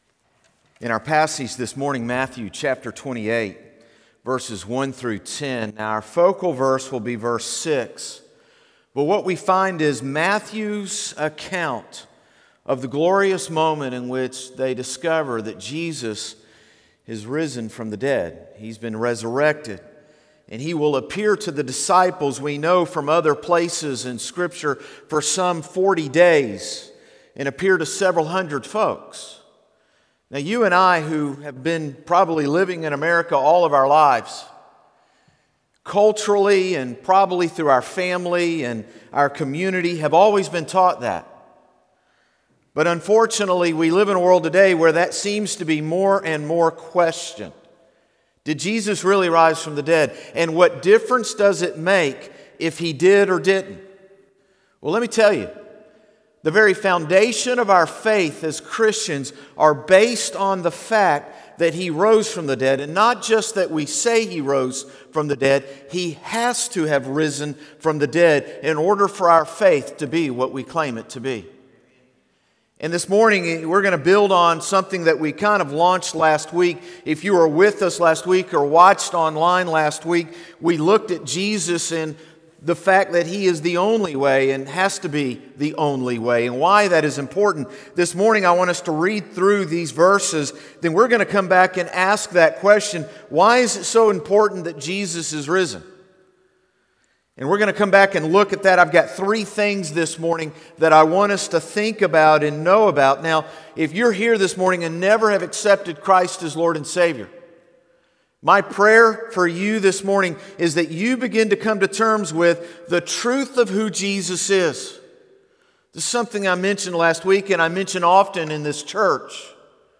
Morning Service - He Is Risen! | Concord Baptist Church
Sermons - Concord Baptist Church